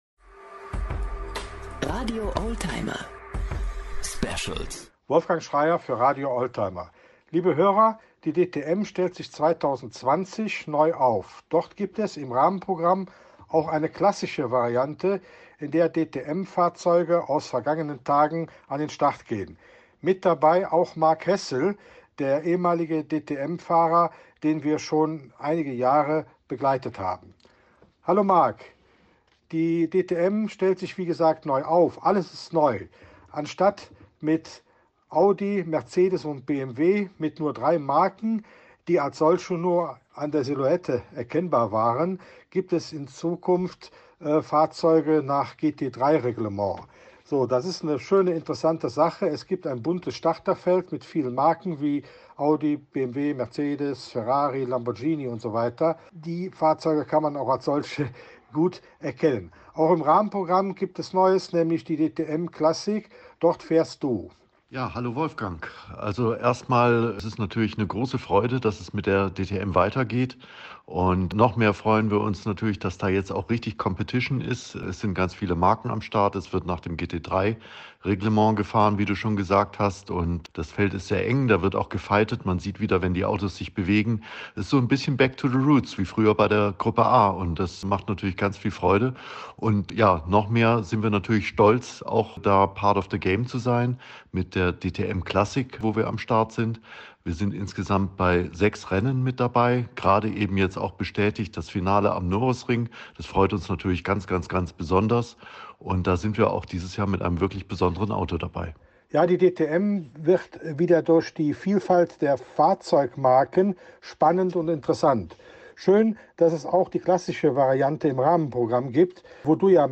Info lieber im kurzen Interview-Podcast